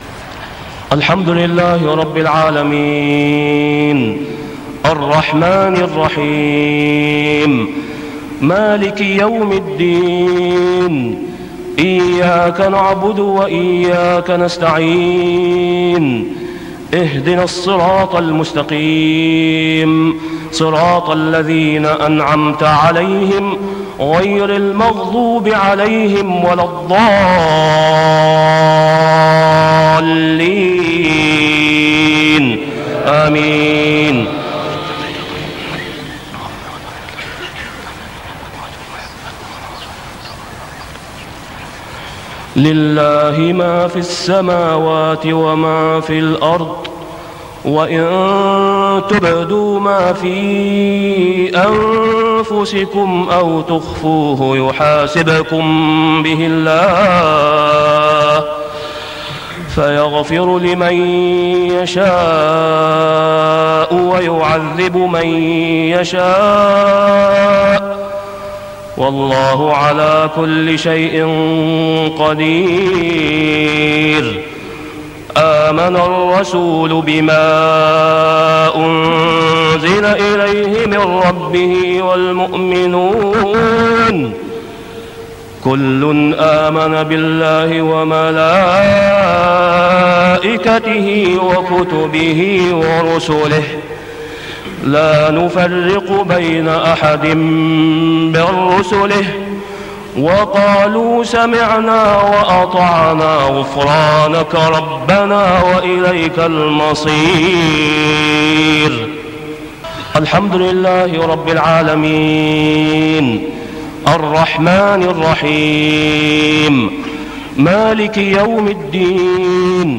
صلاة العشاء فترة الحج عام 1421هـ | من سورة البقرة 285-286 > 1421 🕋 > الفروض - تلاوات الحرمين